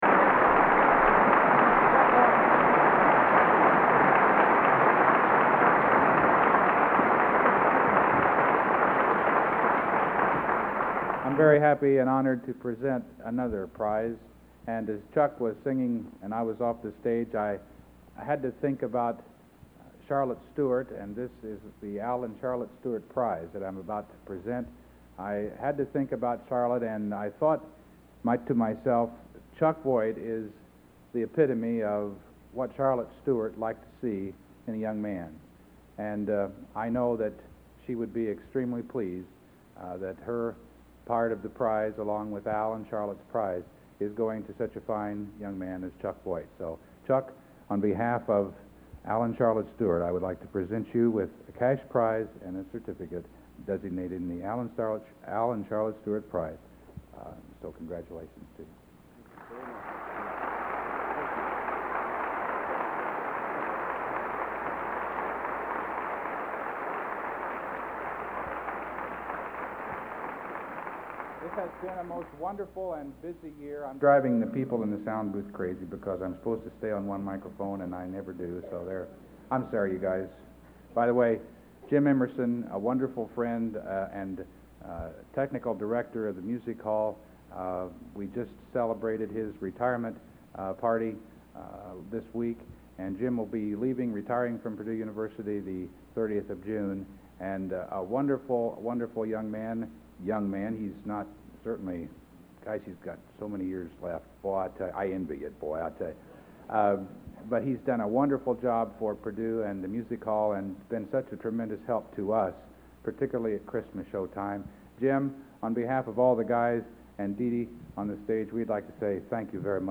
Collection: End of Season, 1987
Location: West Lafayette, Indiana
Genre: | Type: Director intros, emceeing